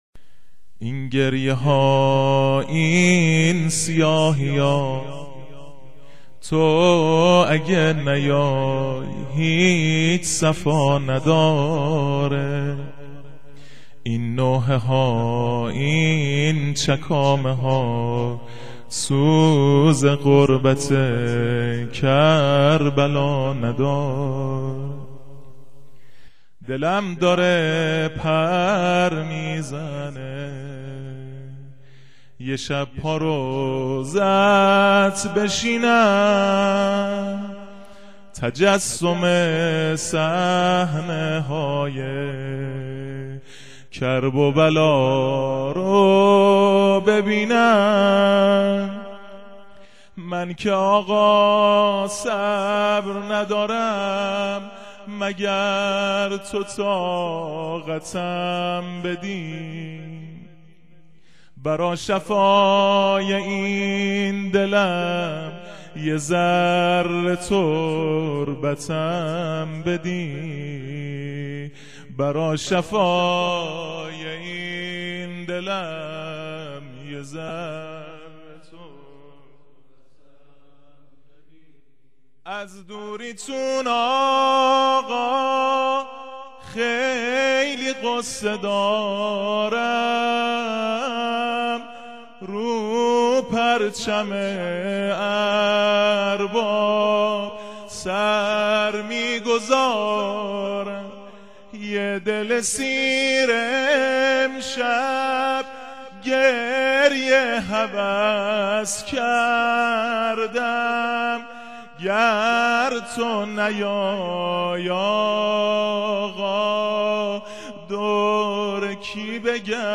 زمزمه محرم - مناجات با امام زمان (عج) -( اين گريه ها اين سياهيا تو اگه نياي هيچ صفا نداره )